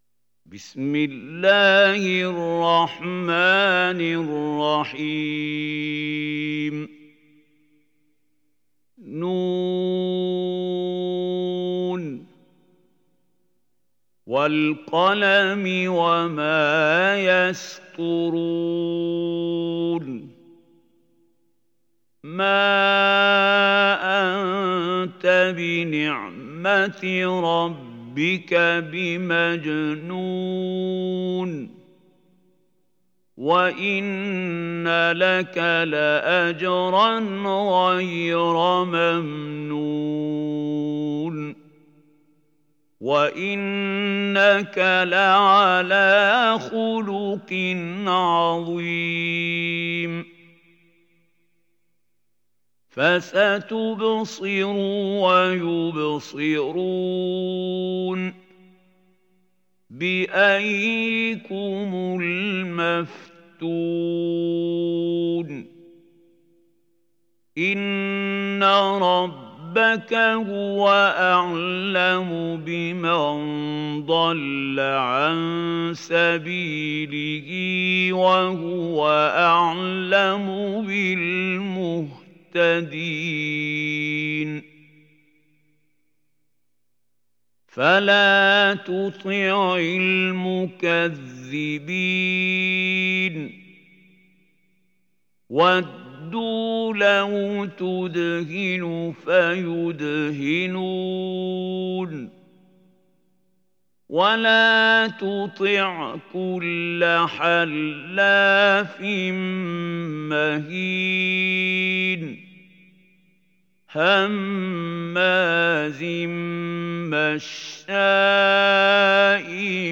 Kalem Suresi mp3 İndir Mahmoud Khalil Al Hussary (Riwayat Hafs)